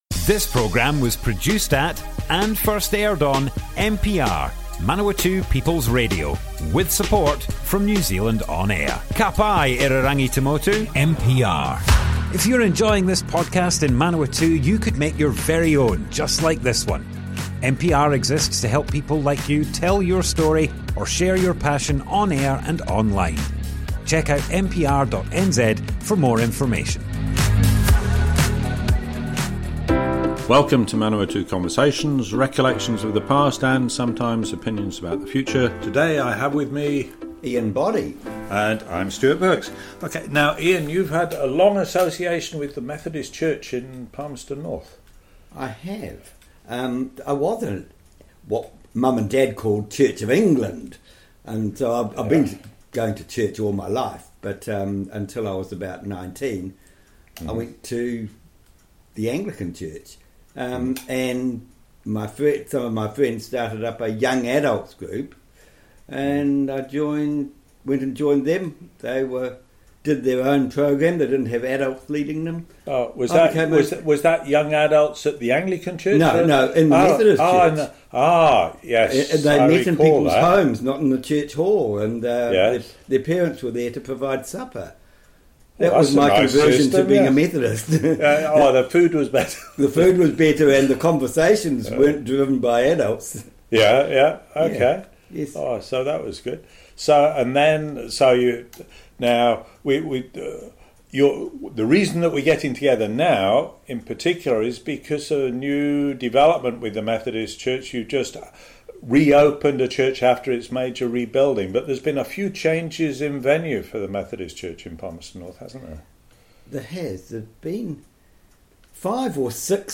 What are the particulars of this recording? Manawatu Conversations More Info → Description Broadcast on Manawatu People's Radio, 12th November 2024.